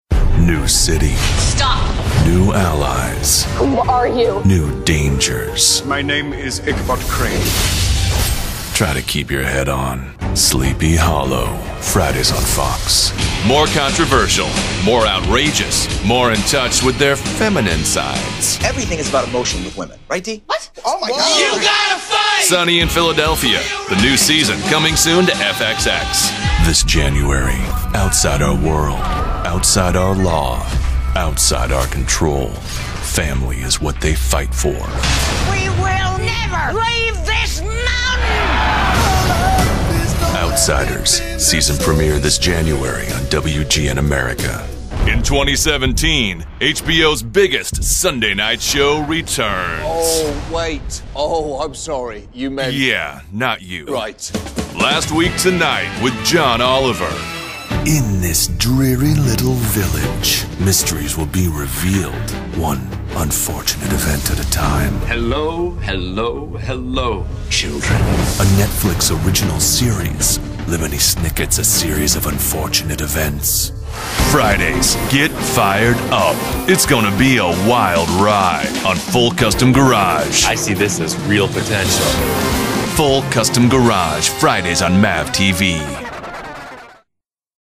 Persuasive, Positive, Real.
Promo